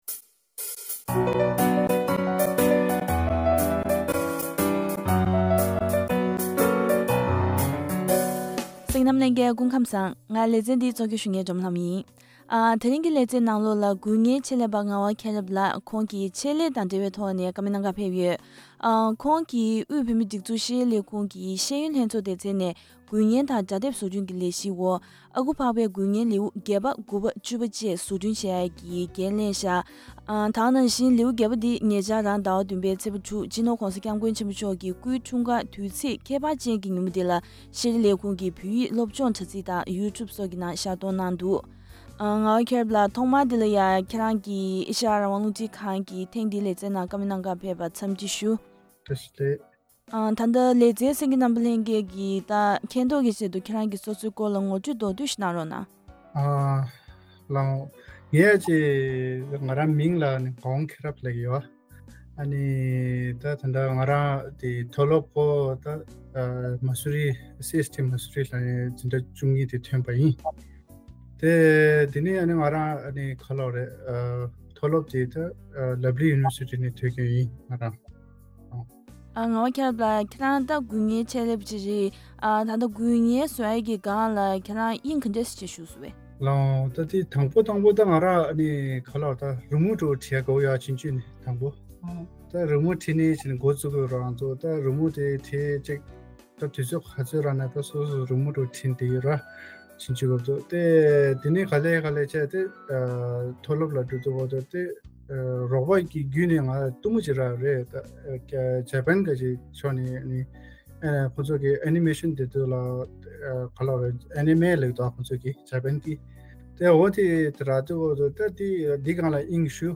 བཀའ་འདྲི་ཞུས་པ་ཞིག